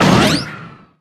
marig_turret_spawn_01.ogg